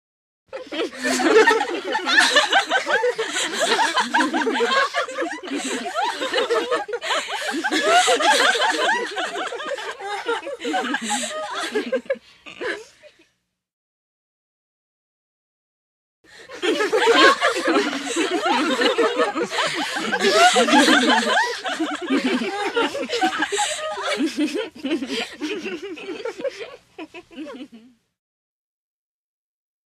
HUMAN VOICES & SOUNDS TEENAGE GIRLS: INT: Giggling with mouths open, constant laughter.